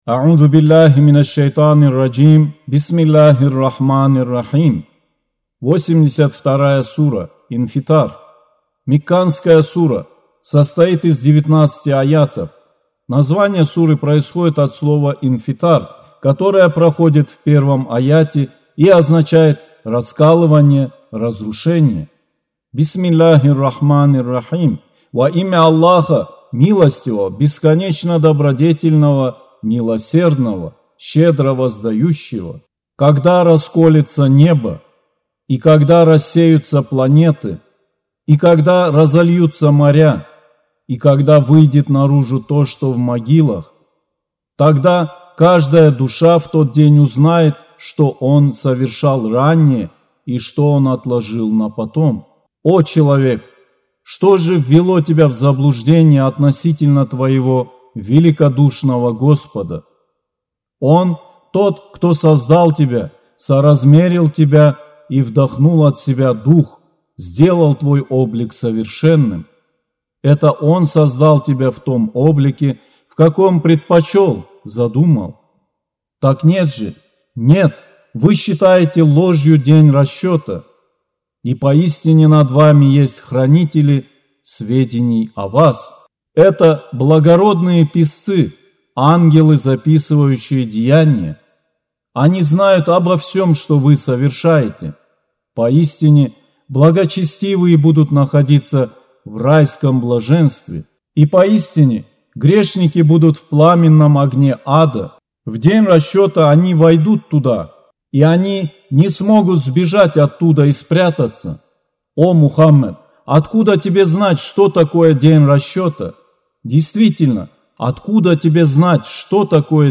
Аудио Коран 82.